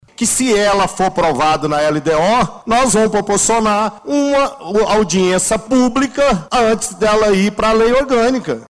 Clique e Ouça Vereador Dilé